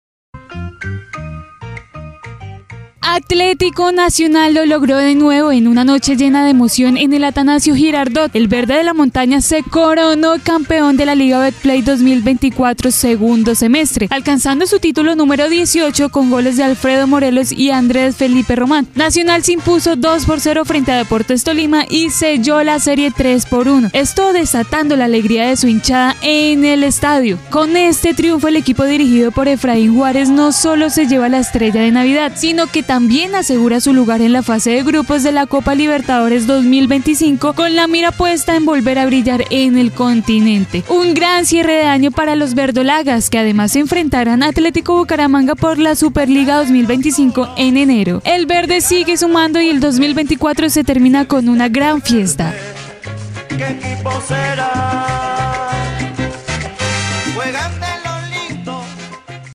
Nota_Deportiva_Nacional_.mp3